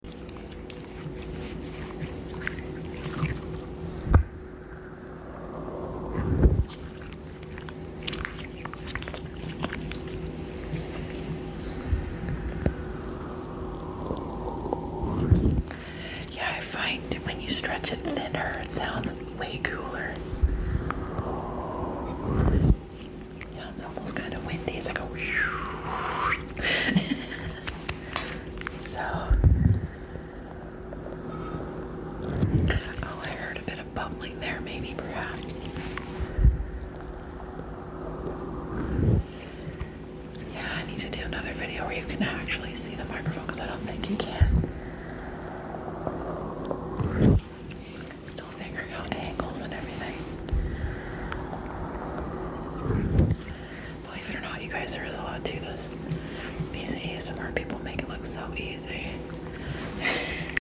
ASMR 🎙 Whispering Ramble with sound effects free download
ASMR 🎙 Whispering Ramble with Slime 🧫